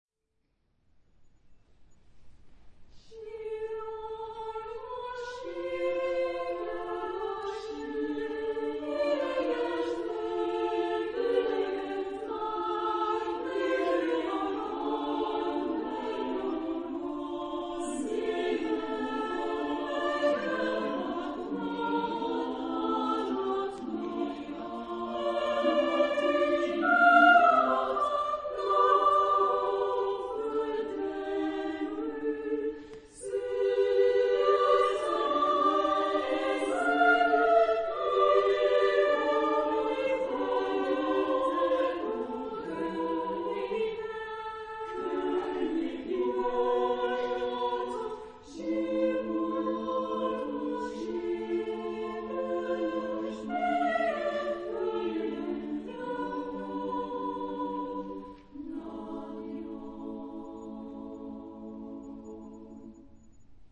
Genre-Style-Forme : Chanson ; Profane